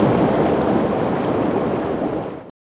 burrow.wav